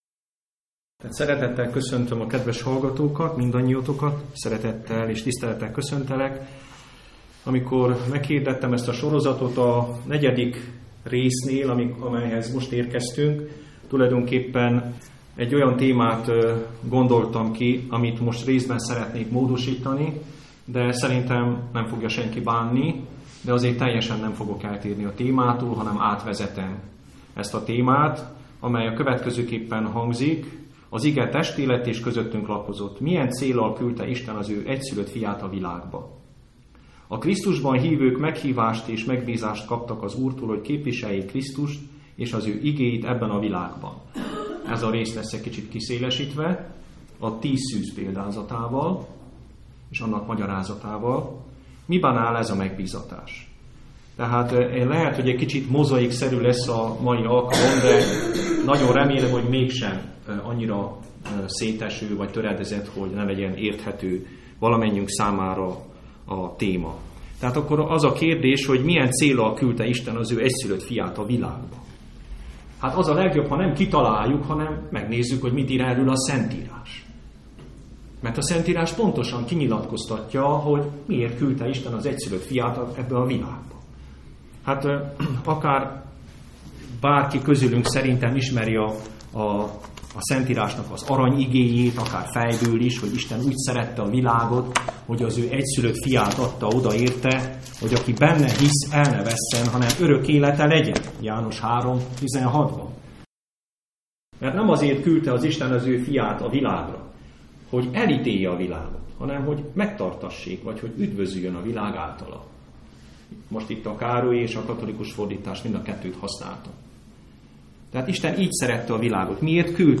A tíz szűzről szóló példázatról szóló előadás hanganyaga itt érhető el!